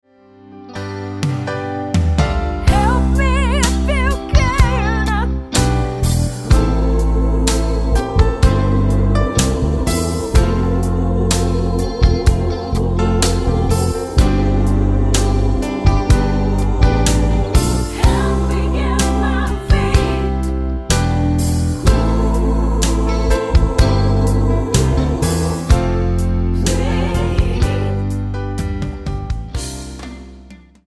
Tonart:C mit Chor
Die besten Playbacks Instrumentals und Karaoke Versionen .